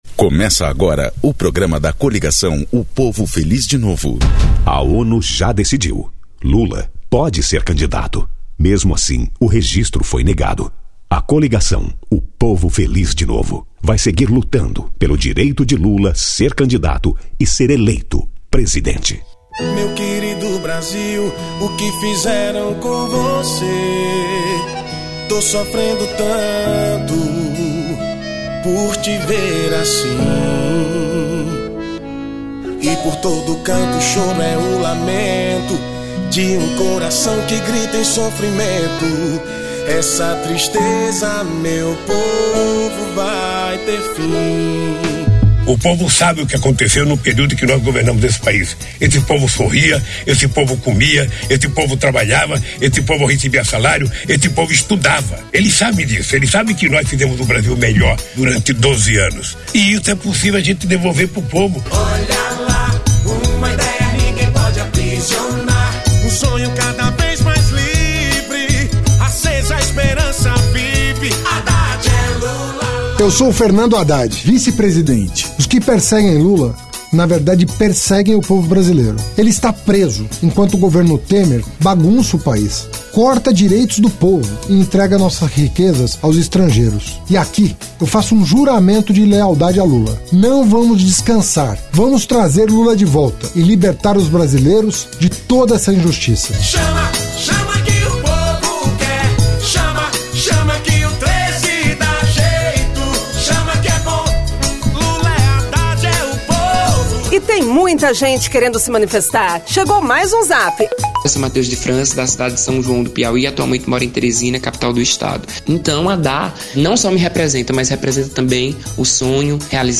Descrição Programa de rádio da campanha de 2018 (edição 03) - 1° turno